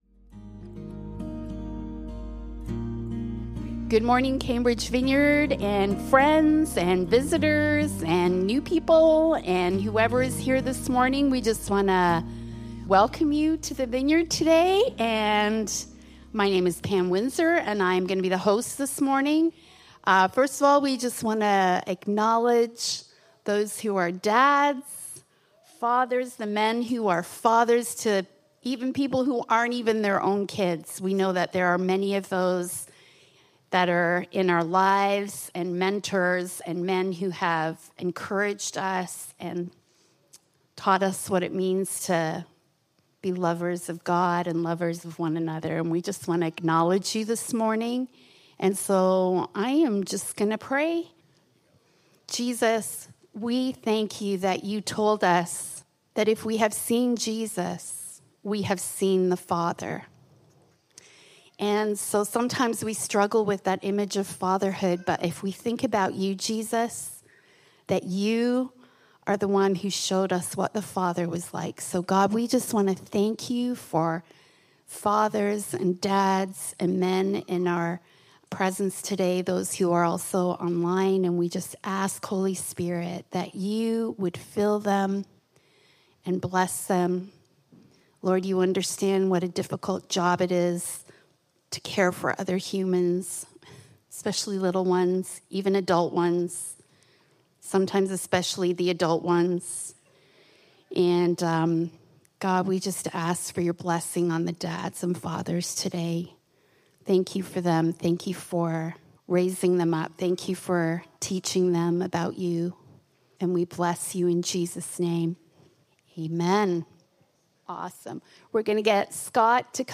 Passage: Genesis 1:29-2:3, Matthew 28: 18-20 Service Type: Sunday Morning